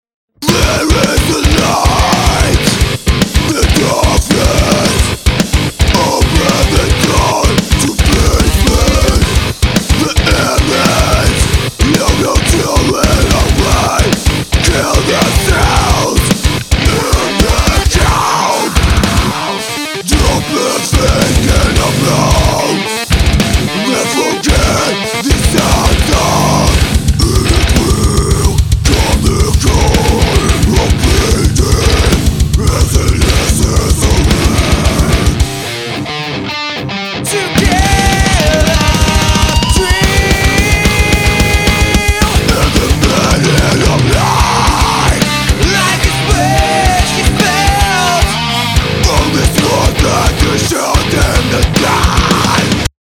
Metalcore\Le456\AmpegSVX